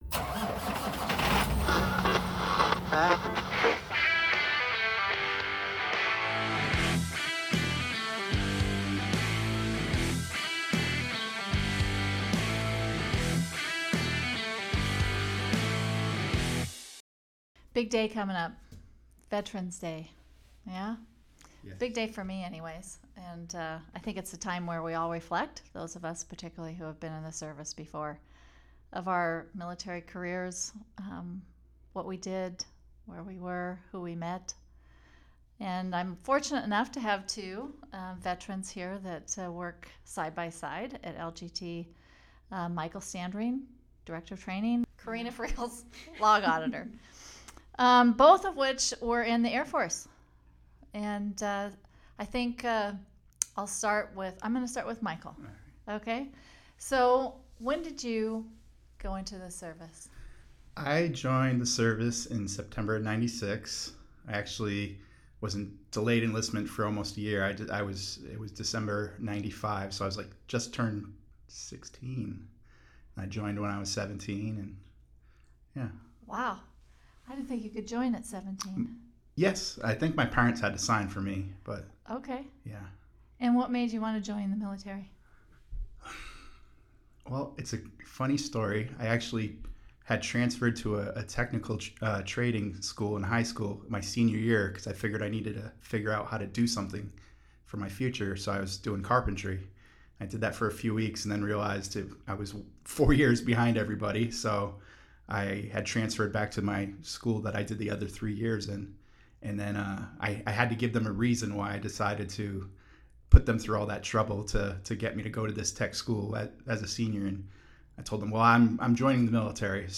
1 Coast to Coast Fresh: Canadian Voices & New Songs 56:33 Play Pause 1h ago 56:33 Play Pause Play later Play later Lists Like Liked 56:33 This week on Songwriters From Here and Away, we dish up all-new Canadian tracks — from rock anthems to tender folk ballads, each song a fresh voice. Tune in for a sonic road-trip across the country with writers who know how to tell a story, build a groove, and strike a chord.